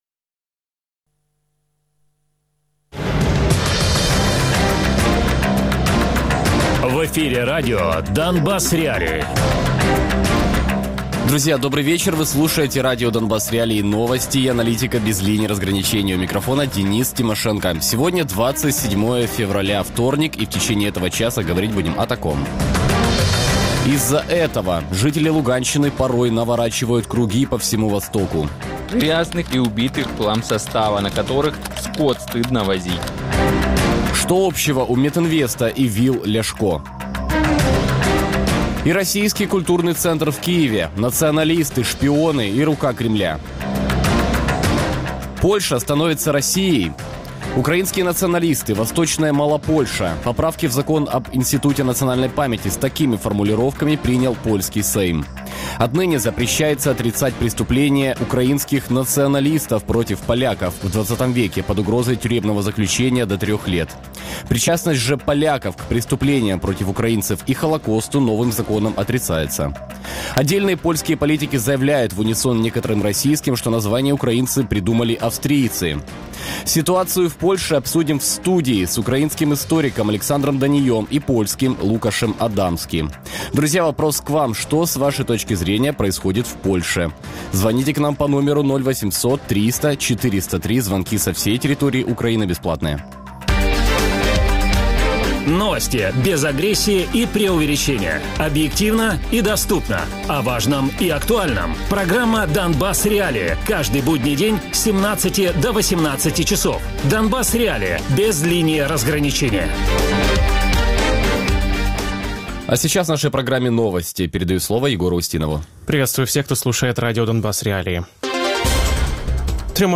польський історик Радіопрограма «Донбас.Реалії» - у будні з 17:00 до 18:00.